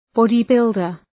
Shkrimi fonetik {‘bɒdı,bıldər}
bodybuilder.mp3